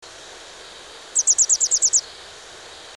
Ballerina gialla - Oasi Valle del Brusà